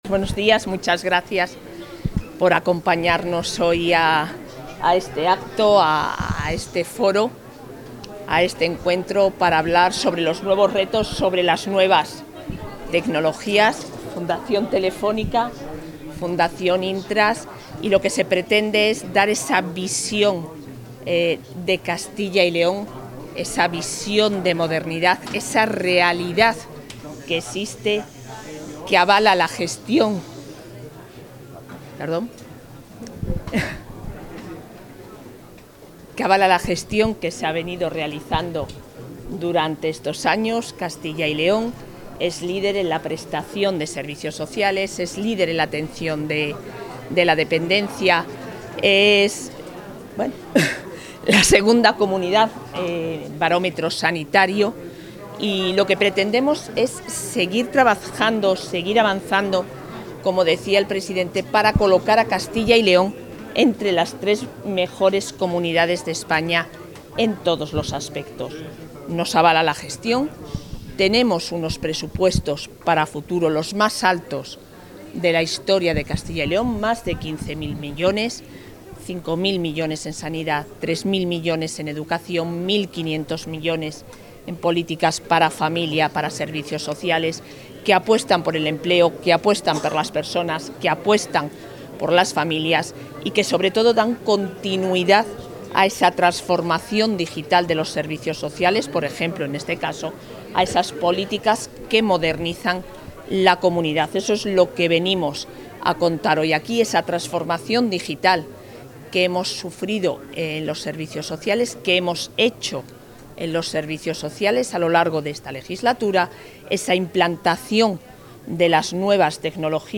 Declaraciones de la vicepresidenta.
La vicepresidenta de la Junta de Castilla y León y consejera de Familia e Igualdad de Oportunidades, Isabel Blanco, ha participado esta mañana en el Club de Prensa de El Mundo de Castilla y León 'Nuevas tecnología para nuevos retos sociales'.